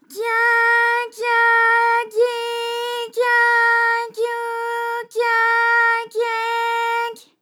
ALYS-DB-001-JPN - First Japanese UTAU vocal library of ALYS.
gya_gya_gyi_gya_gyu_gya_gye_gy.wav